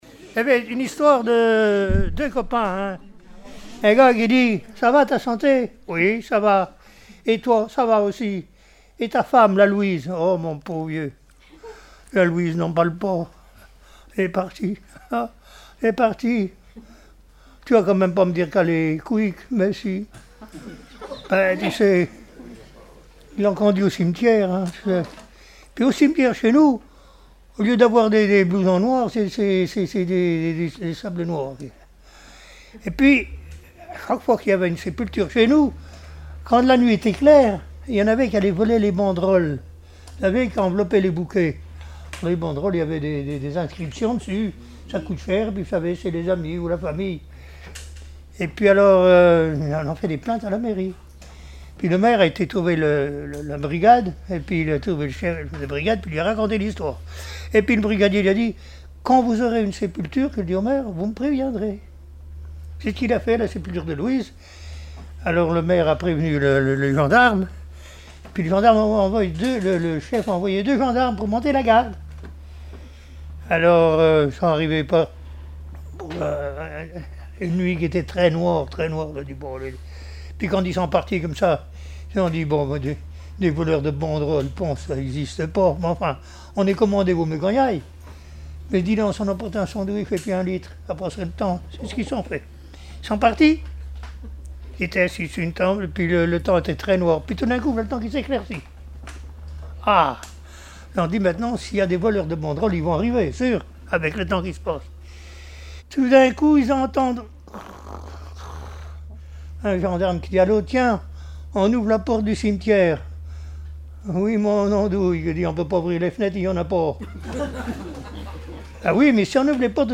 Mémoires et Patrimoines vivants - RaddO est une base de données d'archives iconographiques et sonores.
Genre sketch
Catégorie Récit